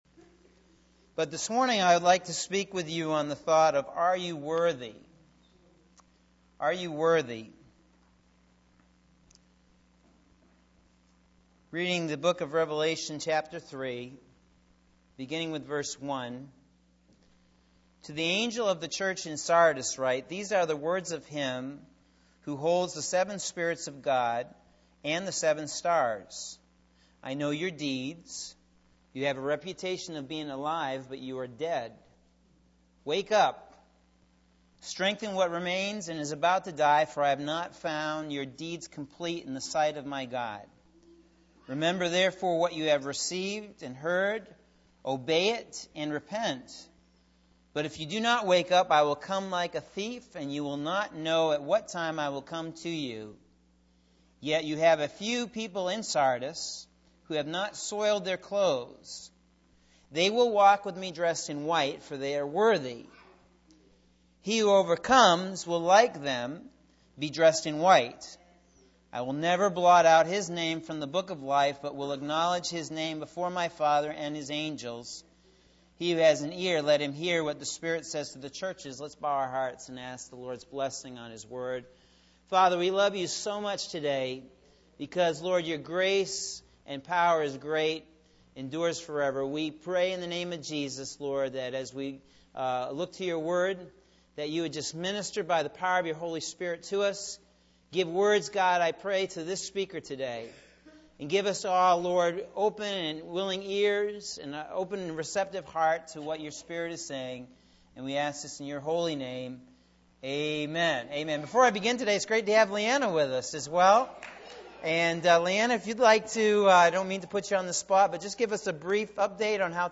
Sunday October 4th AM Sermon – Norwich Assembly of God